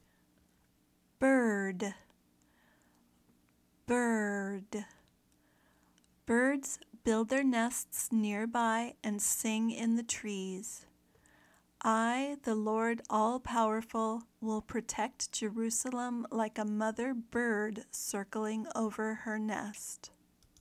/bɜːrd/ (noun)